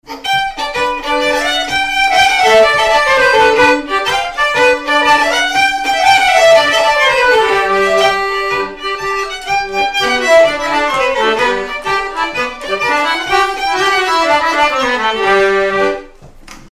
danse : matelote
violon
Pièce musicale inédite